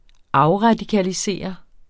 Udtale [ ˈɑwʁɑdikaliˌseˀʌ ]